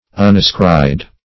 ([u^]n`[.a]*skr[imac]d")